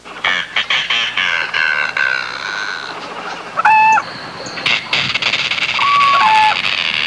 Herons Kites And Cranes Sound Effect
herons-kites-and-cranes.mp3